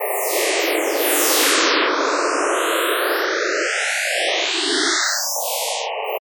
Coagula is a bitmap to sound converter.